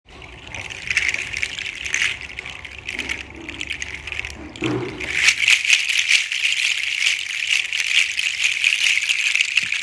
• large seed shaker originally from Togo
• loud hollow clacking sound